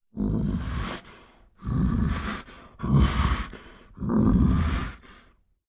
growling-bear-sound